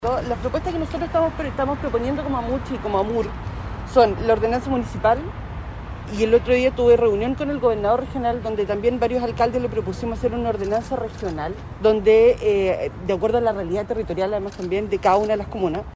En este contexto la vicepresidenta de la Asociación de Municipalidades de Chile y alcaldesa de Colina, Isabel Valenzuela, solicitó una ordenanza única para abordar la crisis.